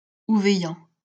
Ouveillan (French pronunciation: [uvɛjɑ̃]